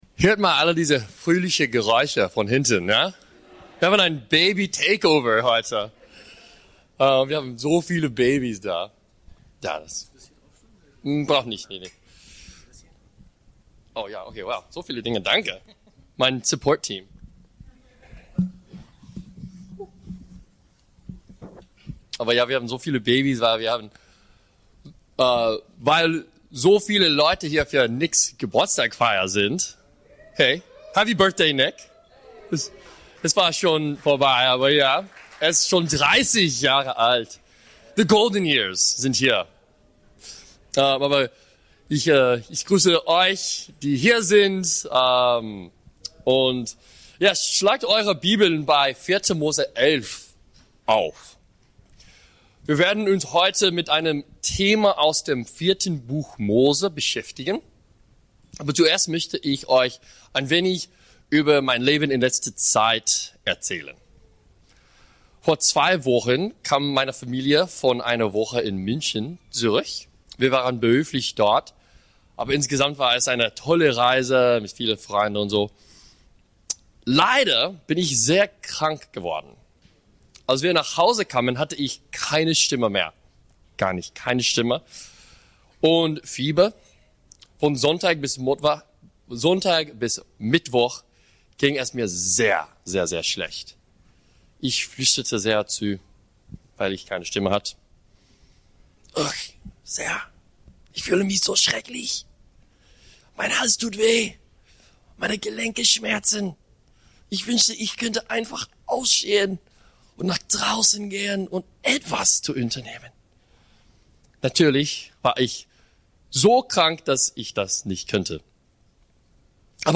Licht sein statt Meckern, Murren, Jammern ~ BGC Predigten Gottesdienst Podcast